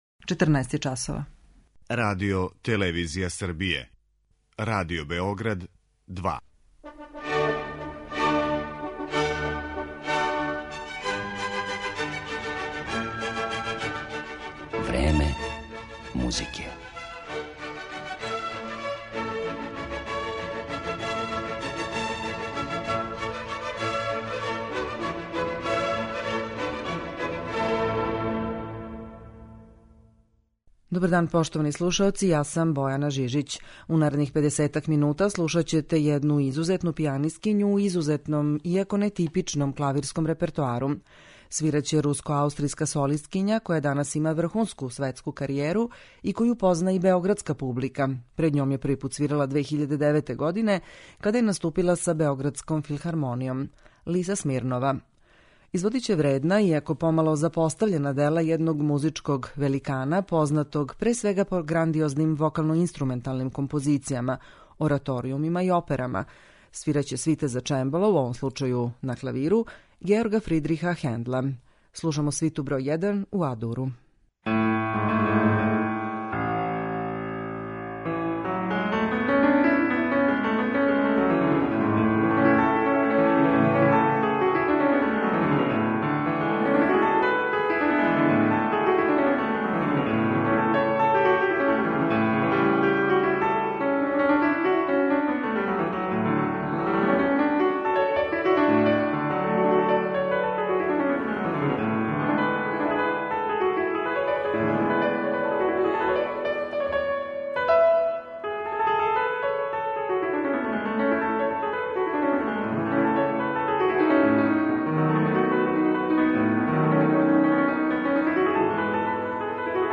клавирских извођења свита за чембало